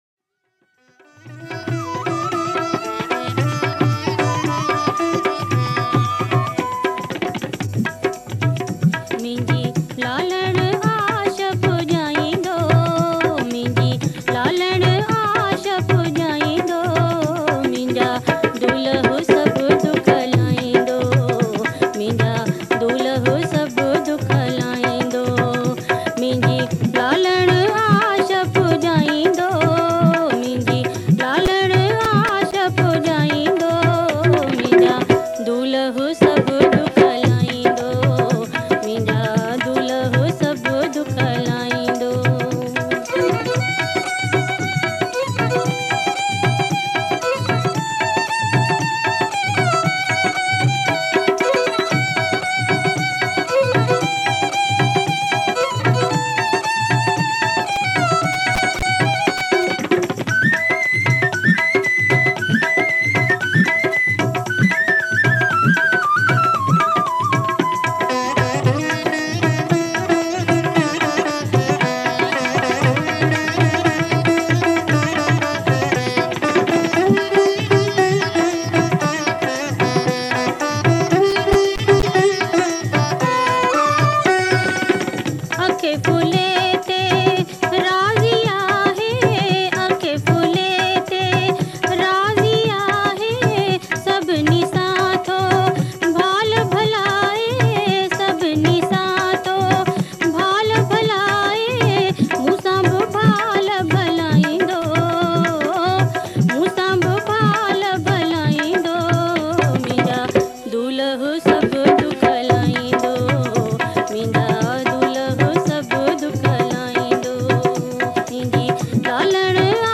Sindhi Jhulelal Geet, Lada, Kalam, Ghazal & Bhajans